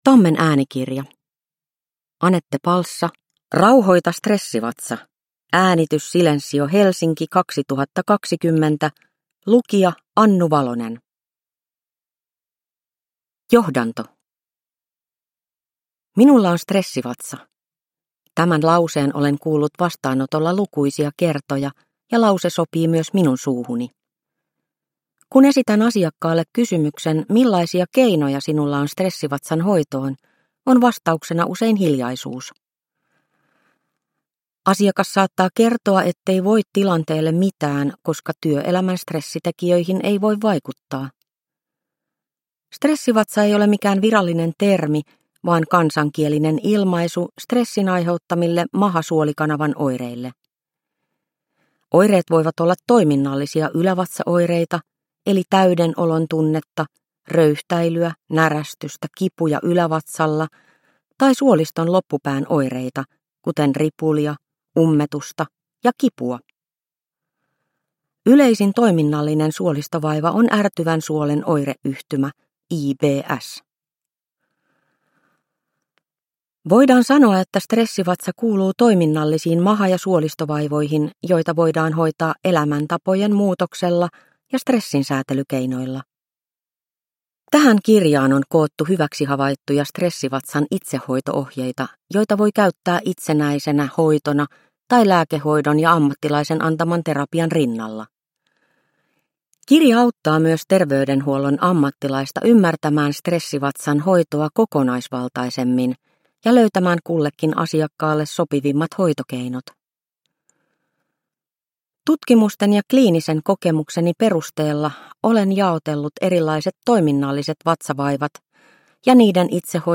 Rauhoita stressivatsa – Ljudbok – Laddas ner